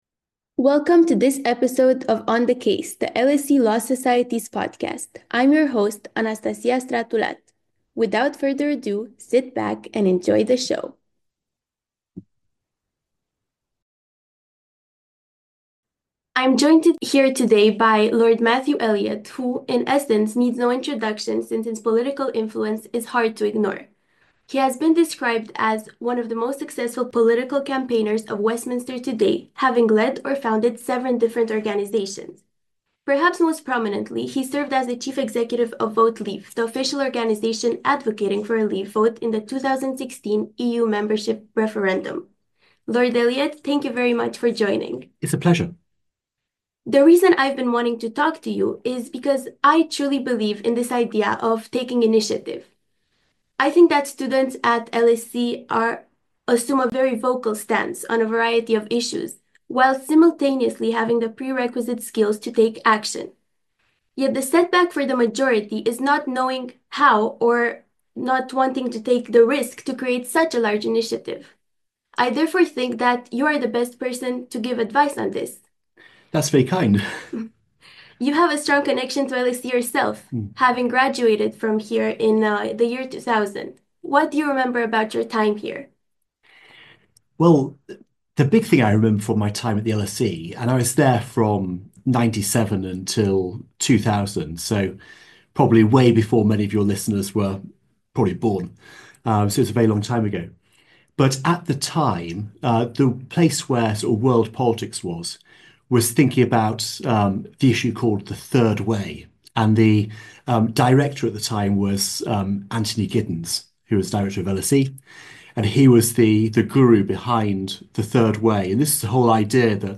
An Interview with Lord Elliott: Let Me Never be Confounded